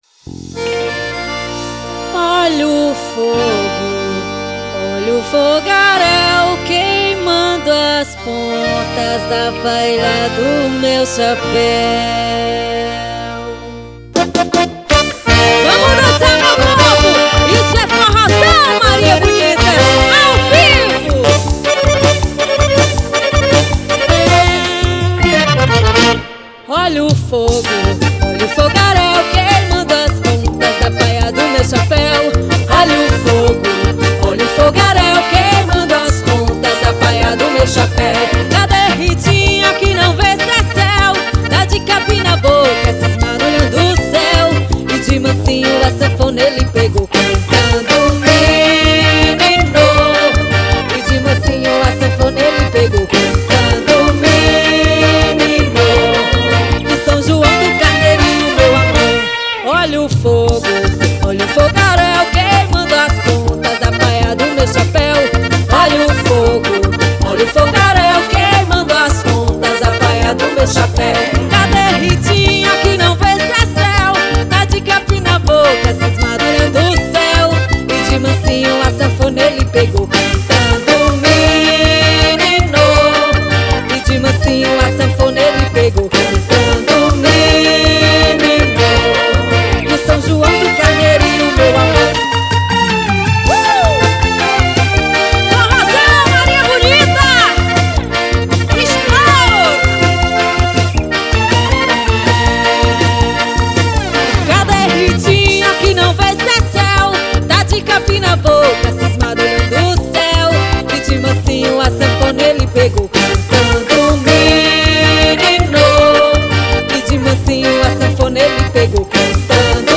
Ao vivo em Fortaleza.